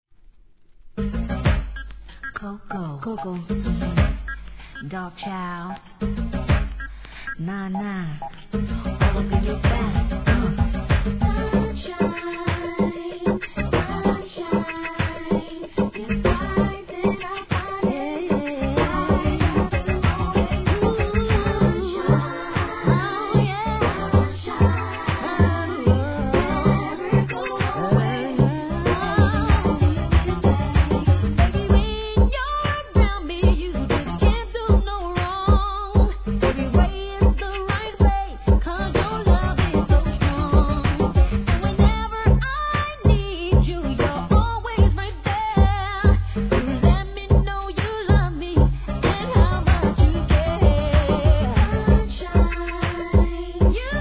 HIP HOP/R&B
DANCEHALL REGGAEのRIDDIMでのブレンド・シリーズ第二段!!